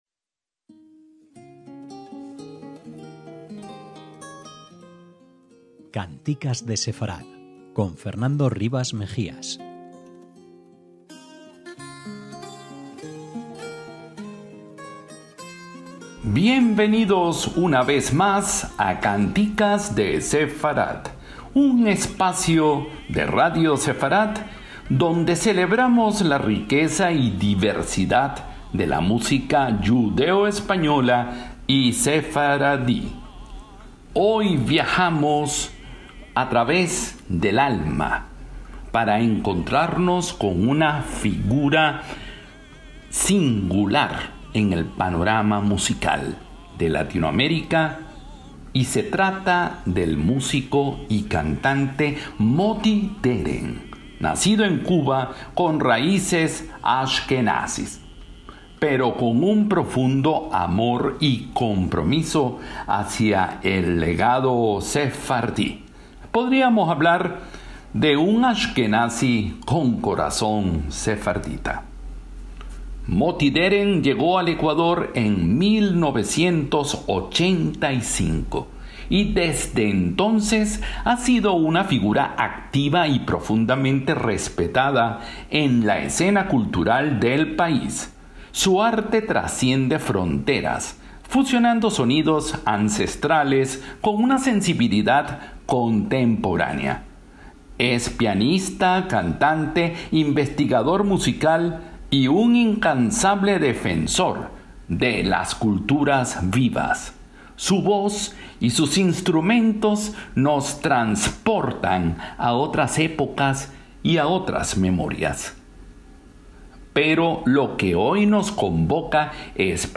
Con su estilo único, mezcla el timbre de la ocarina, la percusión africana del yembé, y